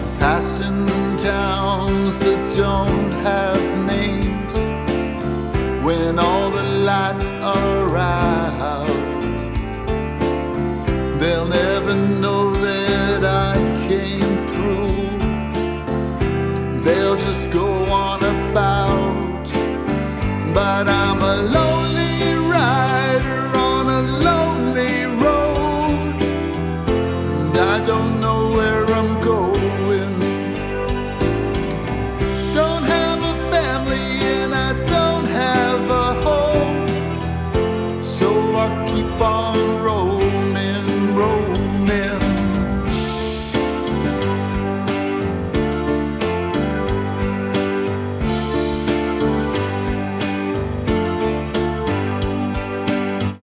A. Vocal Compositions